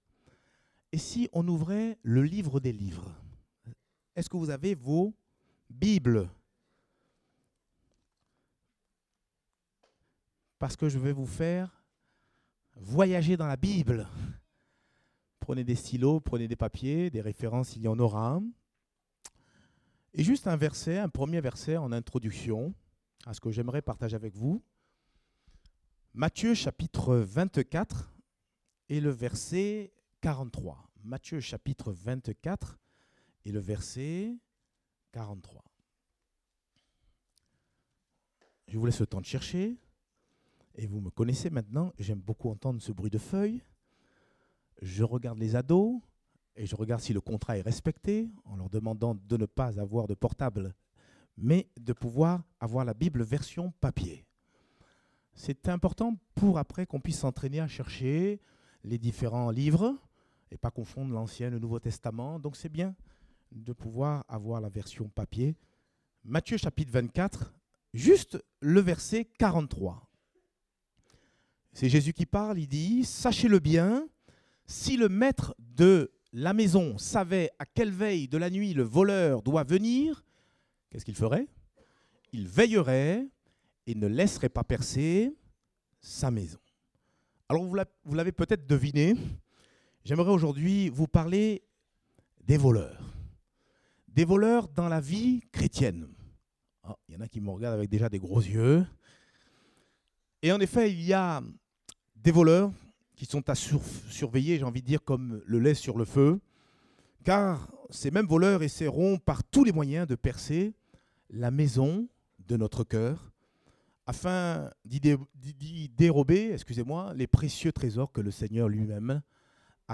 Date : 18 mars 2018 (Culte Dominical)